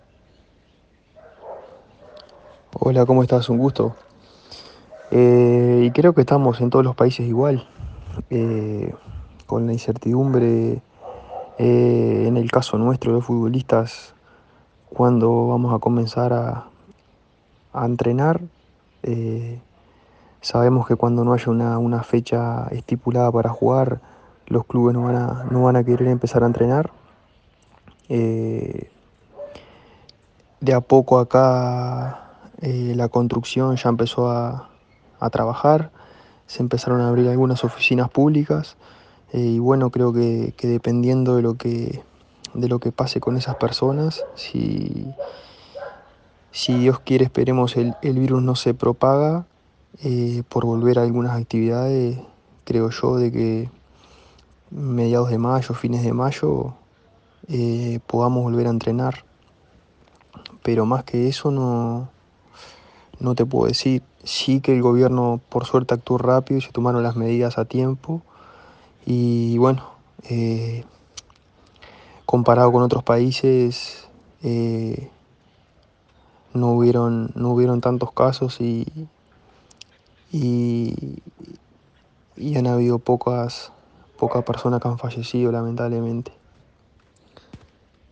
Ping-pong